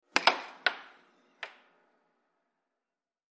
Air Hockey; Puck Hits And Bounce On Table.